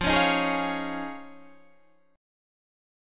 Fm7#5 chord